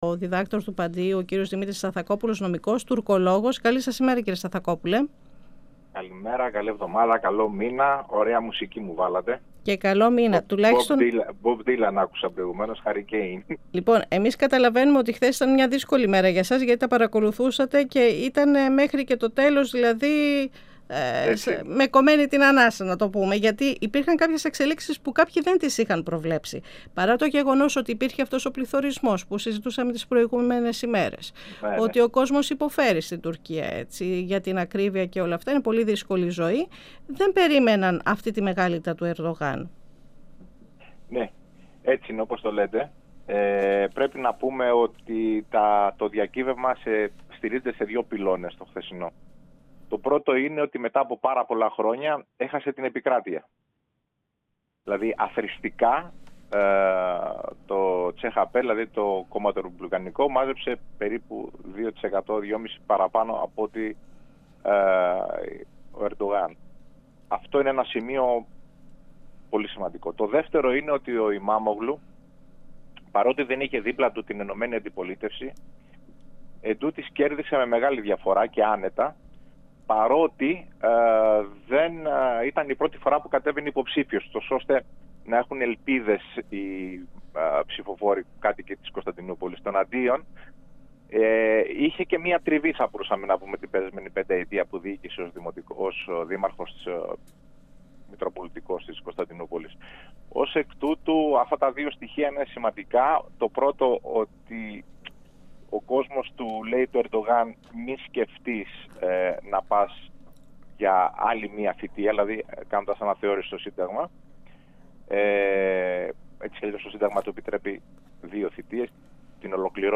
μιλώντας στην εκπομπή «Αίθουσα Σύνταξης» του 102FM της ΕΡΤ3.
Τέτοιους ψήφισαν και όχι τους εκλεκτούς των κομμάτων και δη του κυβερνώντος κόμματος». 102FM Αιθουσα Συνταξης Συνεντεύξεις ΕΡΤ3